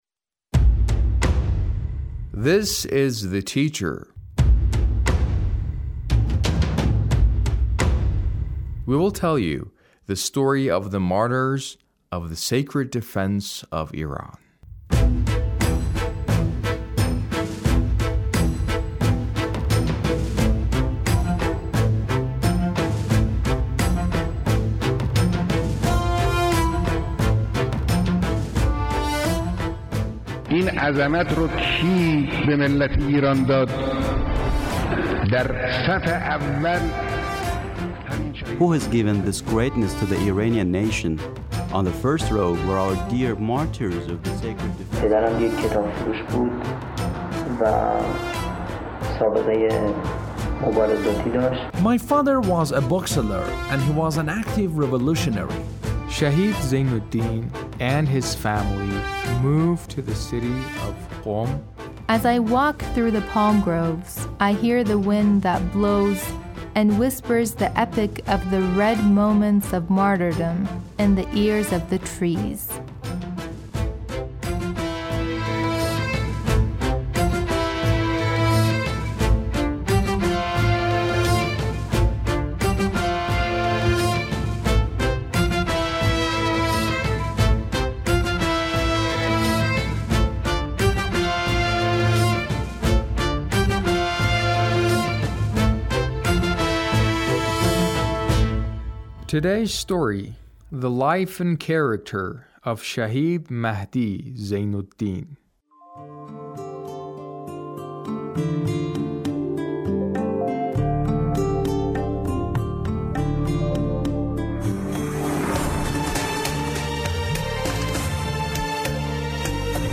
A radio documentary on the life of Shahid Mahdi Zeinoddin- Part 1